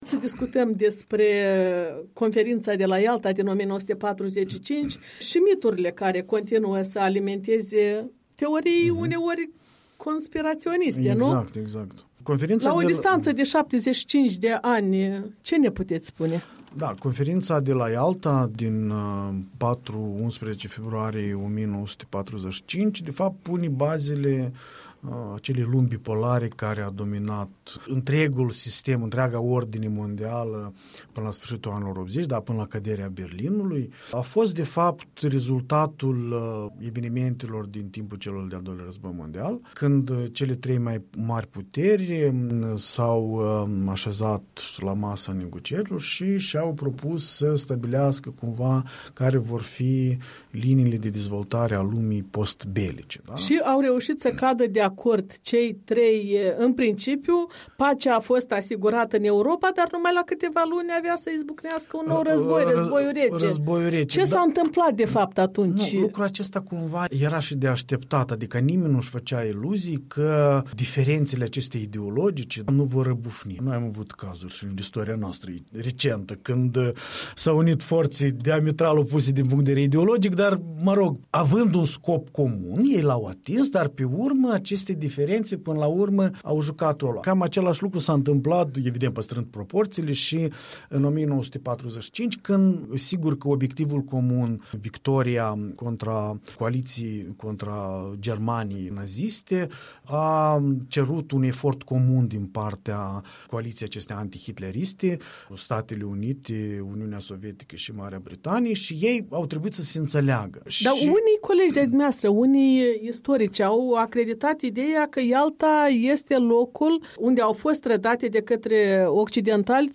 Interviul